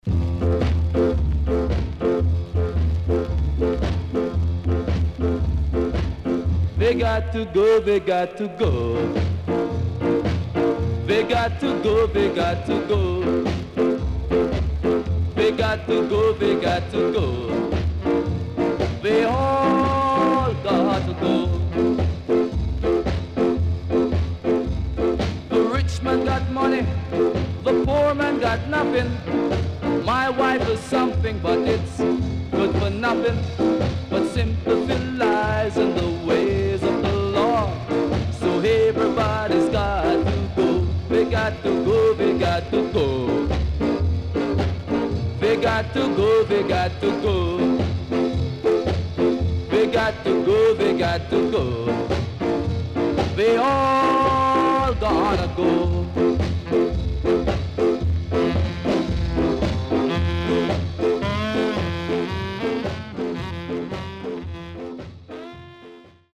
HOME > SKA / ROCKSTEADY  >  SKA  >  EARLY 60’s
CONDITION SIDE A:VG(OK)〜VG+
SIDE A:うすいこまかい傷ありますがノイズあまり目立ちません。